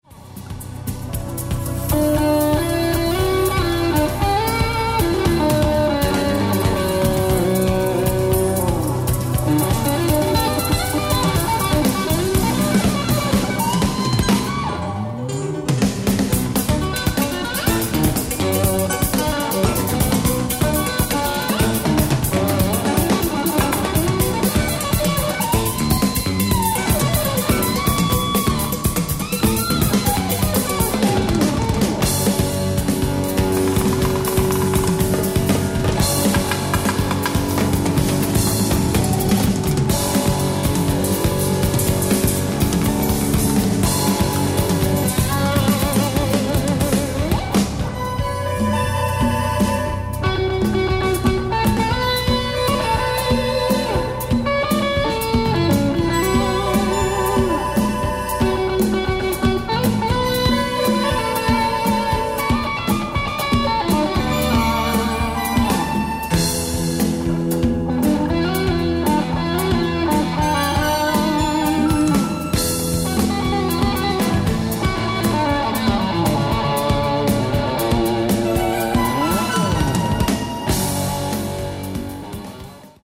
ライブ・アット・パークウエスト、シカゴ、イリノイ 11/18/1987
発掘された放送音源用マスターから初収録！！
※試聴用に実際より音質を落としています。